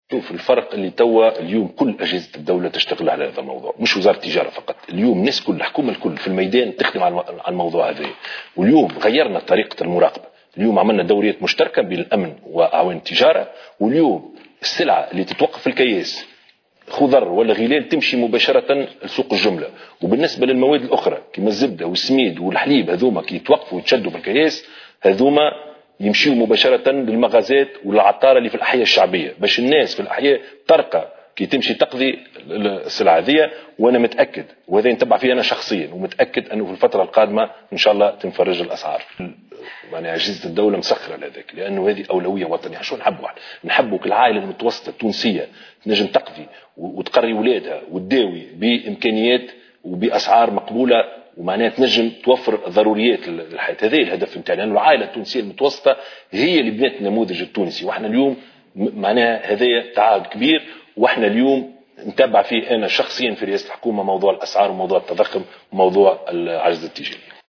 وعبّر الشاهد، في حوار مع قناة التاسعة، اليوم الجمعة 21 ديسمبر 2018، عن ثقته في نجاعة هذه الجهود وقدرتها على التخفيض في الأسعار خلال الفترة القادمة، مشيرا إلى أن أبرز أولويات الدولة في الفترة الحالية تتمثل في التخفيض في نسبة التضخم، وهو موضوع يحظى متابعة يومية ومستمرة في رئاسة الحكومة بحسب تعبيره.